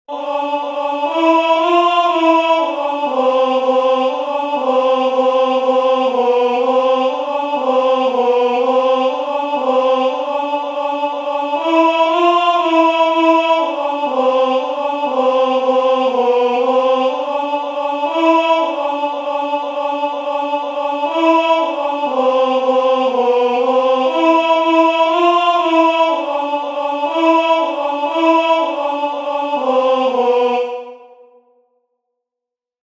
"Descenditque cum illo," the first responsory verse from the second nocturn of Matins, Common of One Martyr